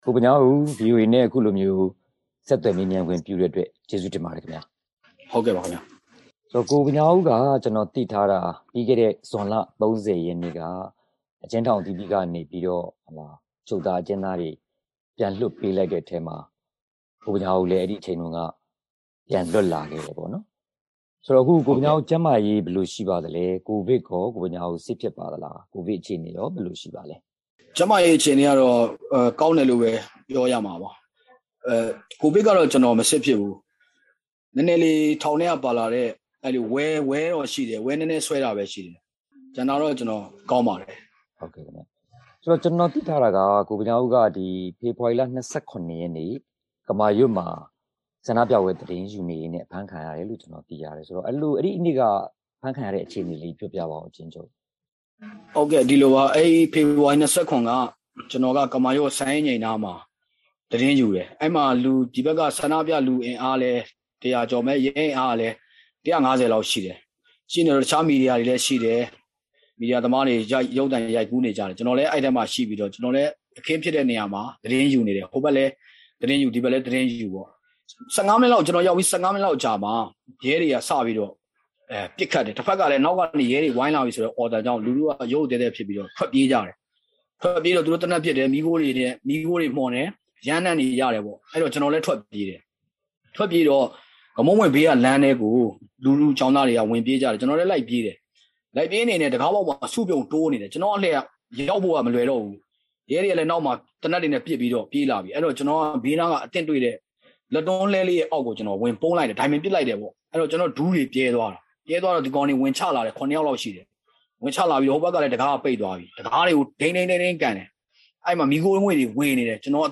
၄ လကျော်ကြာ ဖမ်းဆီးခံခဲ့ရပြီးမှ ပြန်လည်လွတ်မြောက်လာသူ အလွတ်သတင်းထောက်တဦး ဘာတွေ တွေ့ကြုံခဲ့ရတယ်ဆိုတာ ဆက်သွယ် မေးမြန်းထားပါတယ်။